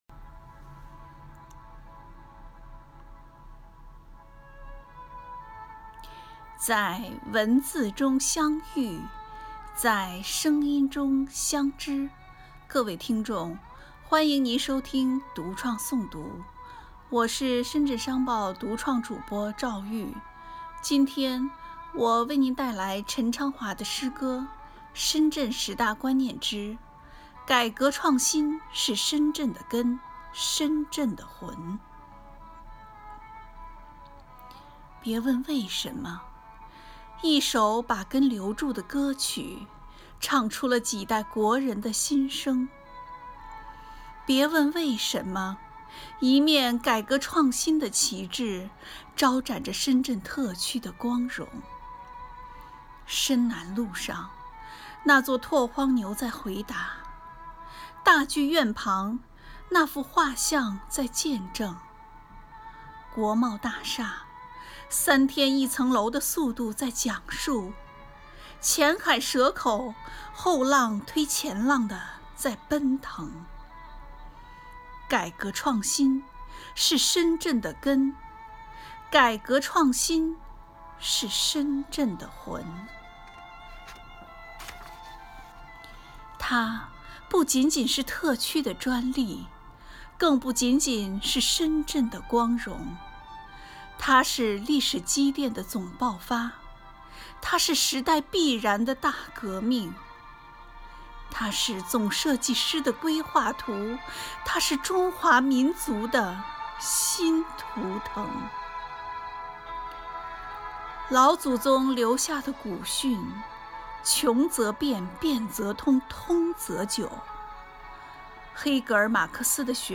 读创诵读(深圳十大观念)｜改革创新是深圳的根，深圳的魂
诗歌